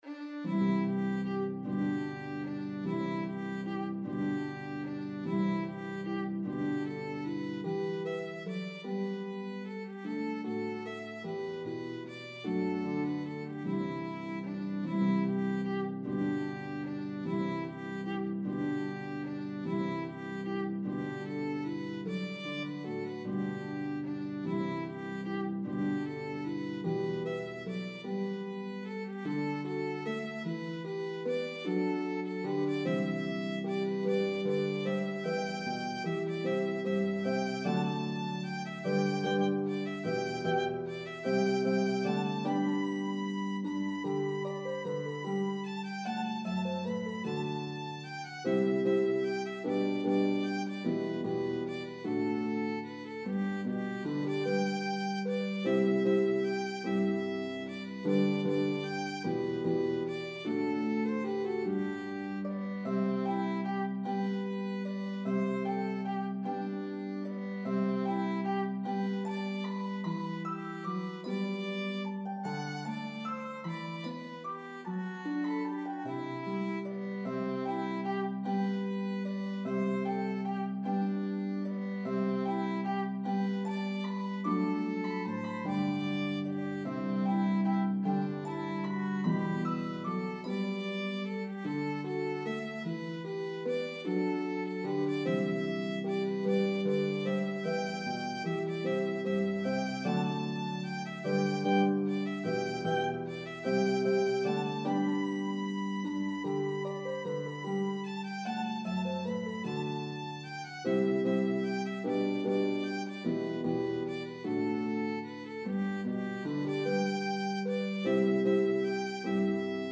A beautiful air that is performed often at weddings.